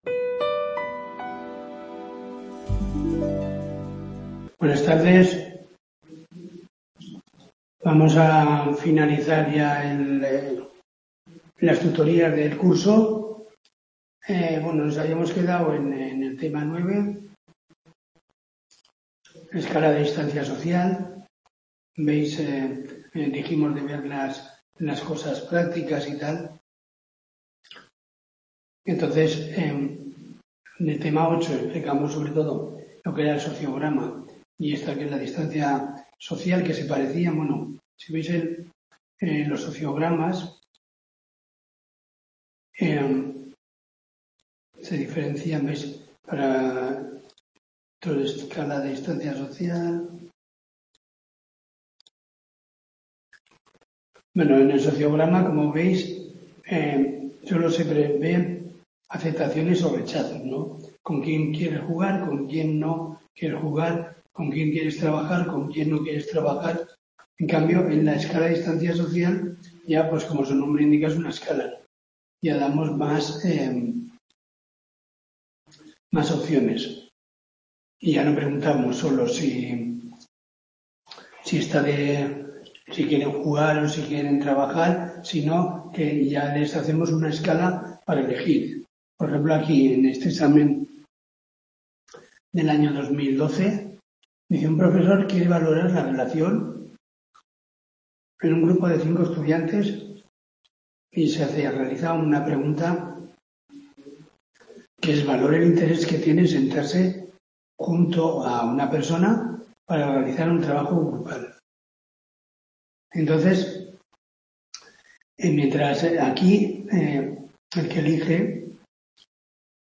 TUTORÍA DE 15/01/2025